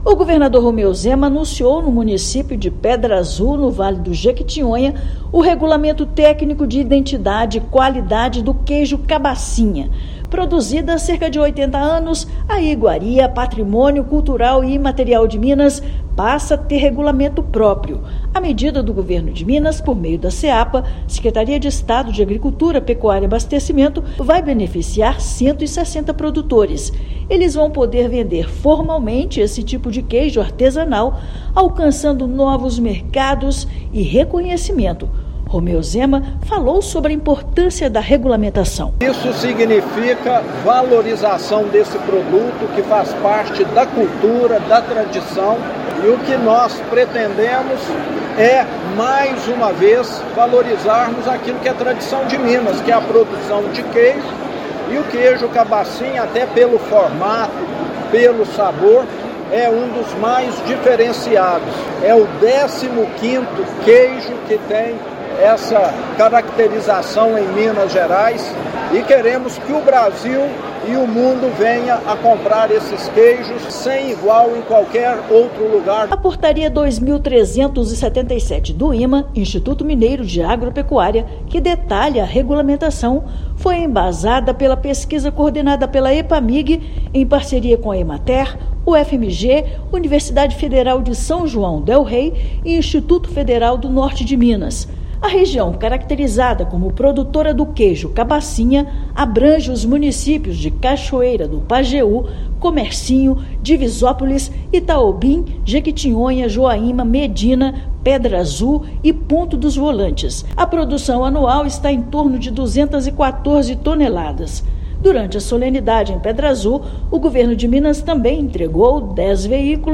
[RÁDIO] Governo de Minas anuncia regulamentação do Queijo Cabacinha, patrimônio cultural e imaterial do estado
Medida vai viabilizar comercialização formal e ampliação de mercados do queijo artesanal do Vale do Jequitinhonha. Ouça matéria de rádio.